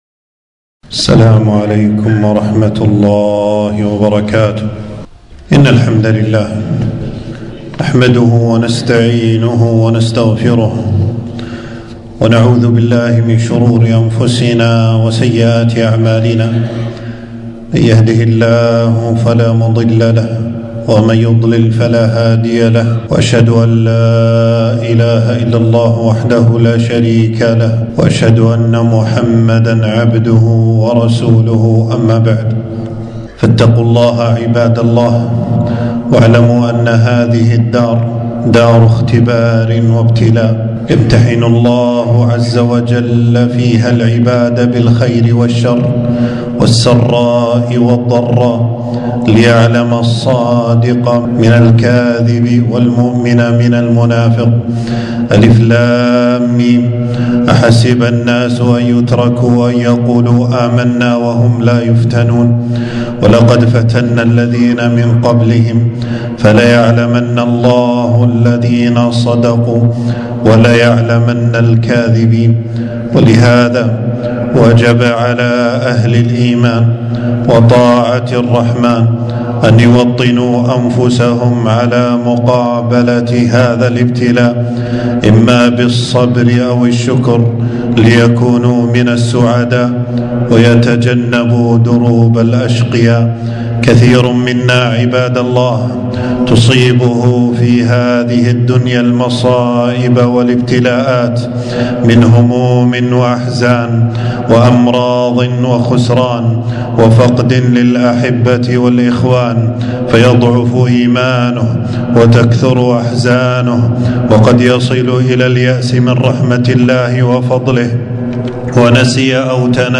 تنزيل تنزيل التفريغ خطبة بعنوان: التوكل على منجاة للعبد.
حفظه الله تعالى المكان: خطبة في يوم 12 جمادى الثاني 1446هـ في مسجد السعيدي بالجهرا.